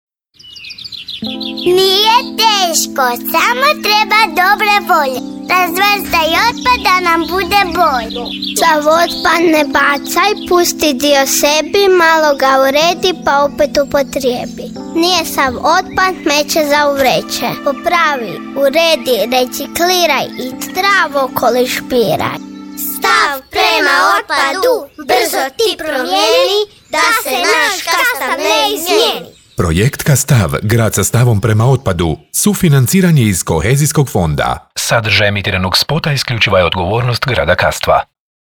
Kako bi ova kampanja imala što bolji odjek, na Radio Kastvu se već dva mjeseca vrti spot koji polako ali sigurno „ulazi u uši“:
Radijski-spot-objava-1.mp3